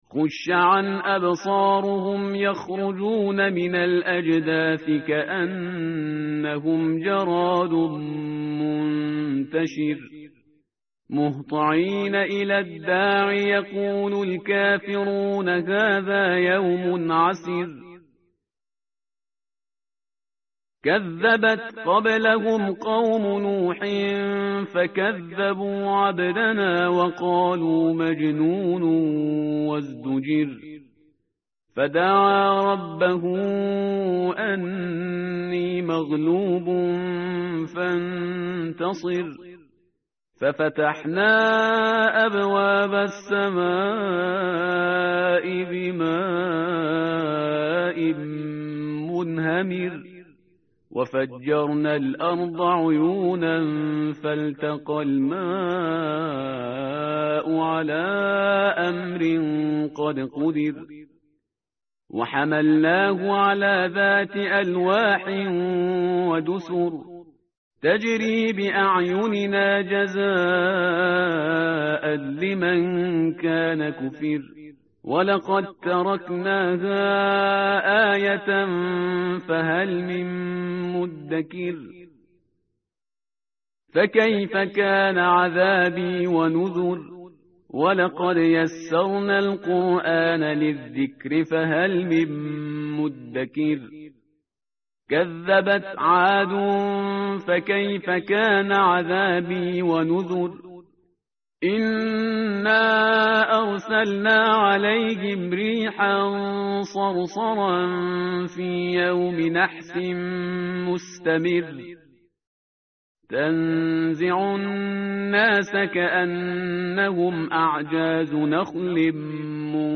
tartil_parhizgar_page_529.mp3